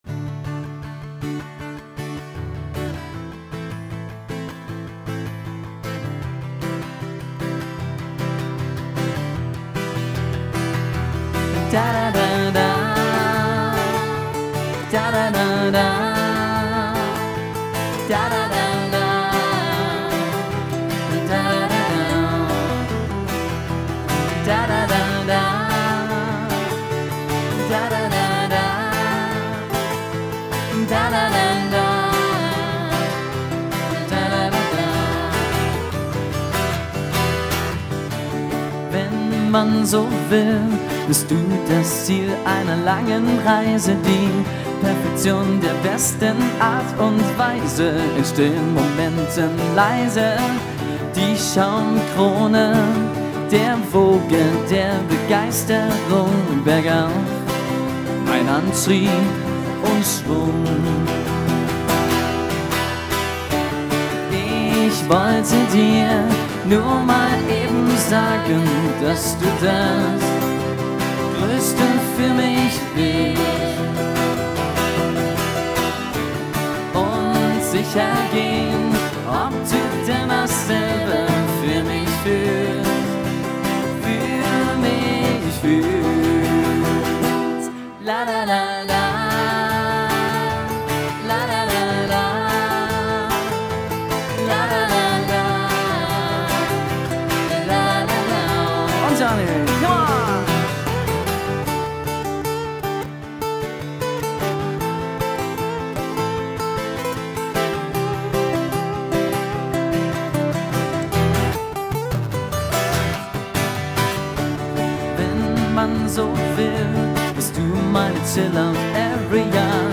Livemusik – Gesang, Gitarre und / oder Klavier
live und unplugged, ohne doppelten Boden.
Mal loungig entspannt, mal rockig